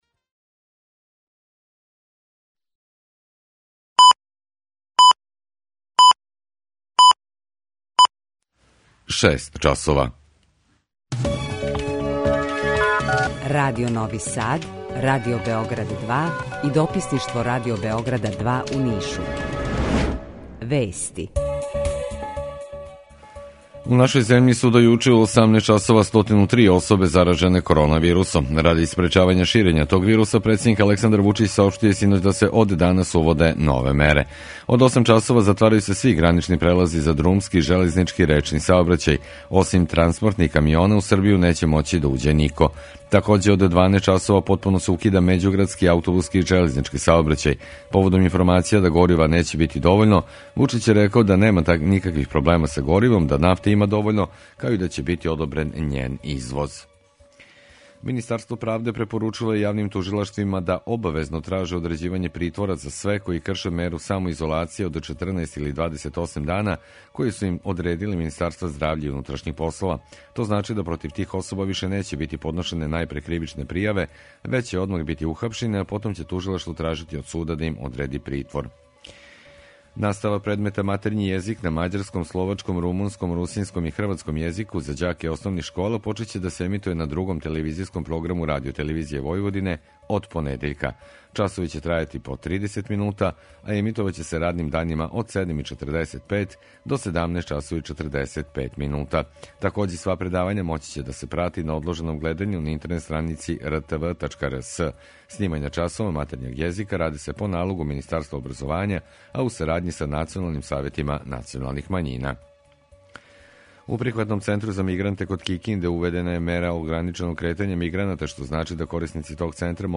У два сата, ту је и добра музика, другачија у односу на остале радио-станице.